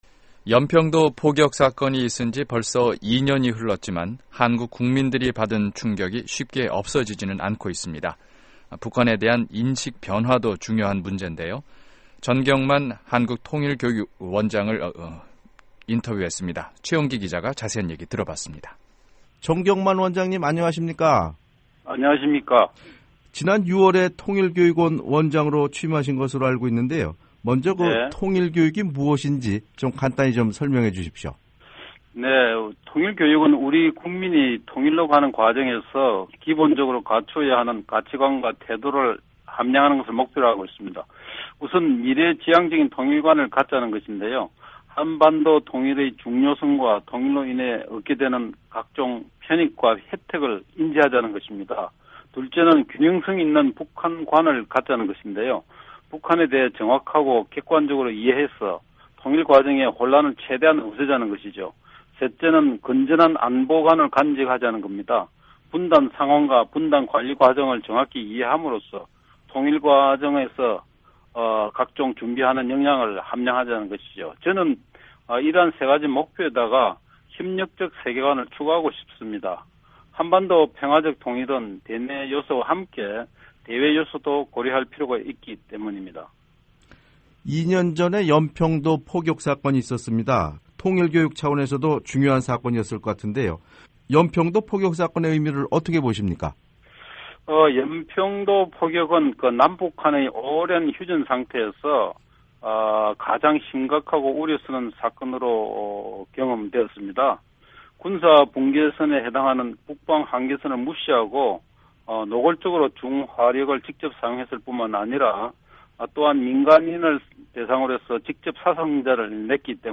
[인터뷰] 전경만 통일교육원장
연평도 포격사건이 있은지 벌써 2년이 흘렀지만 한국 국민들이 받은 충격이 쉽게 없어지지는 않고 있습니다. 북한에 대한 인식 변화도 중요한 문젠데요, 한국의 전경만 통일교육원장을 전화로 연결해서 자세한 얘기 나눠보겠습니다.